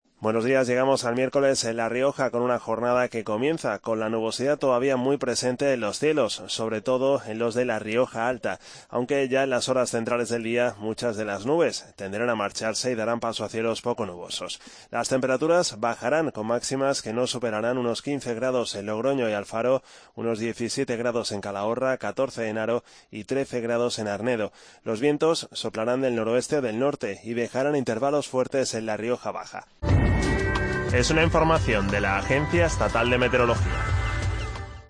AUDIO: Pronóstico.